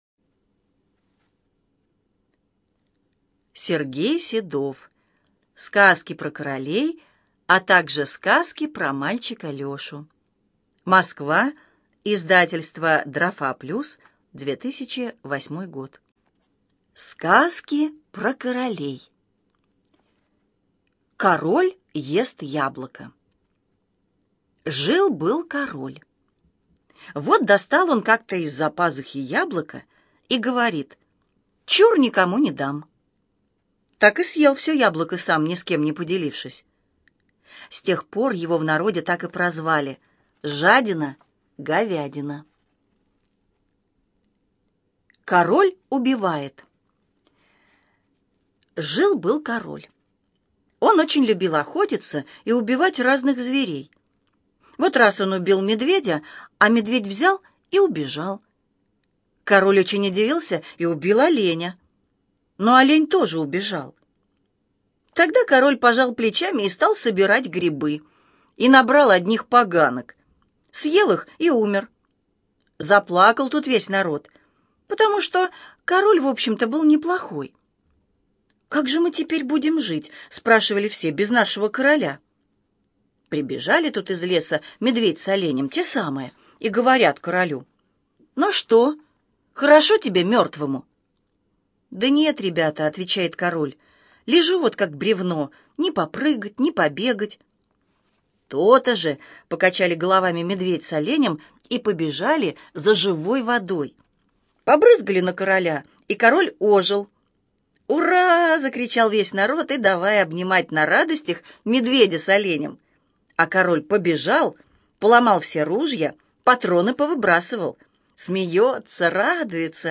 ЖанрДетская литература, Сказки
Студия звукозаписиЛогосвос